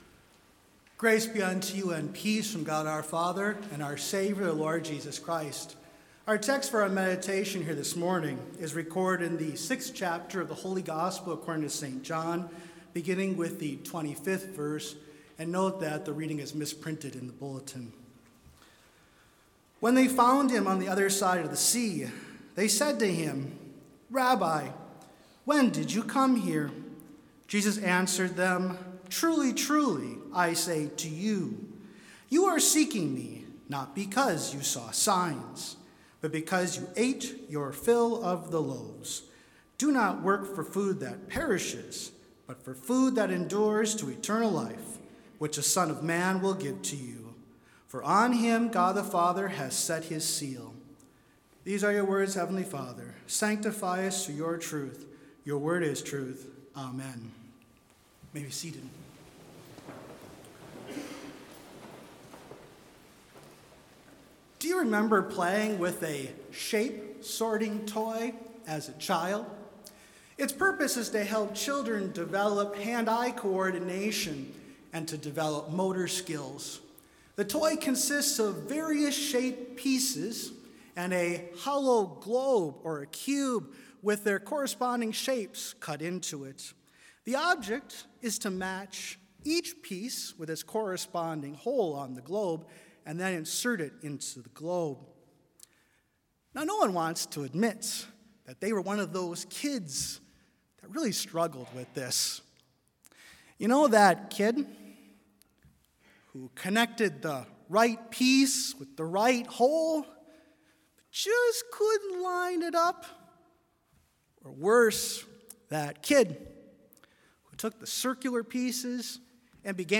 Complete service audio for Chapel - March 28, 2022